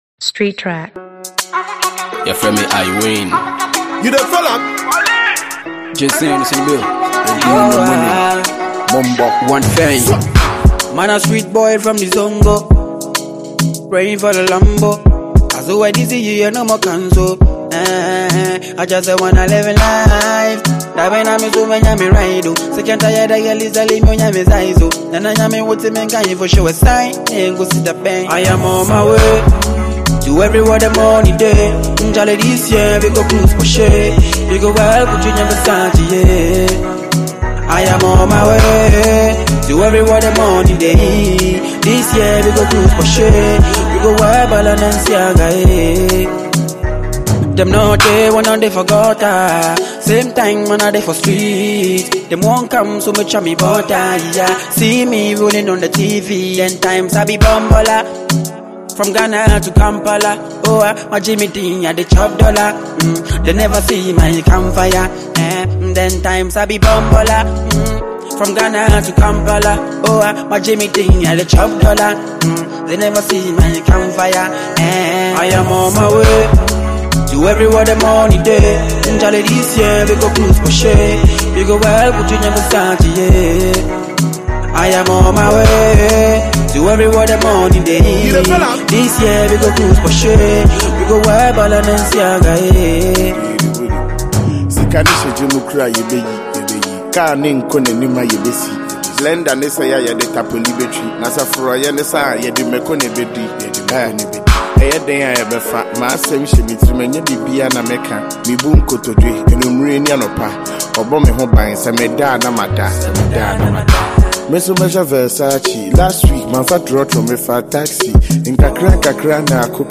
Ghanaian talented music duo
wordsmith rapper